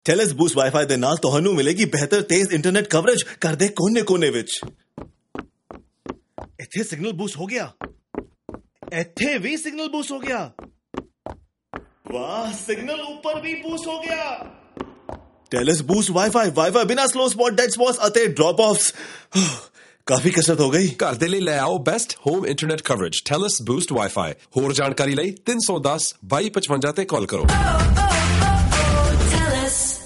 To promote its Boost internet services, binaural radio ads in Cantonese, Mandarin, Hindi and Punjabi moved its narrator around the left and right stereo channels to make it sound like they were moving around the listener’s house, marveling at the signal strength.